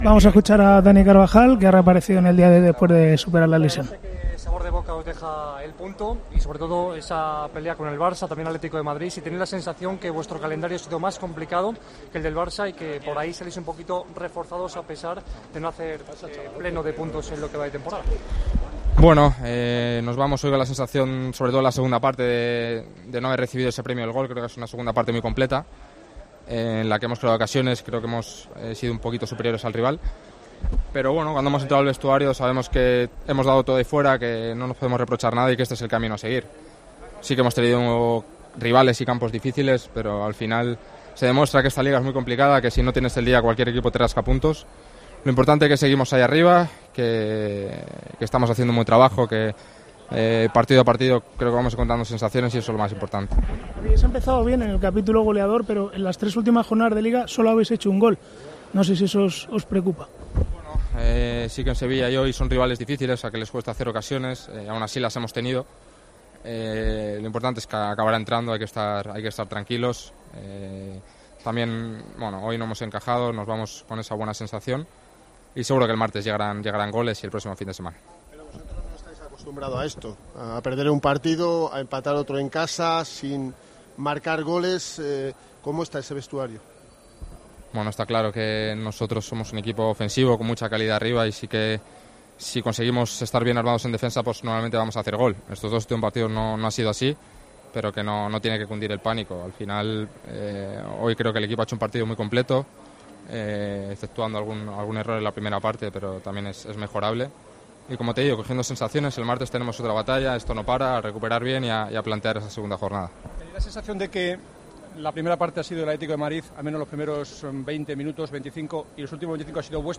Carvajal habla con los medios tras el empate ante el Atleti: "Nos vamos con la sensación de no haber recibido el premio del gol"
"Para ellos un empate es oro", manifestó en la zona mixta del Santiago Bernabéu.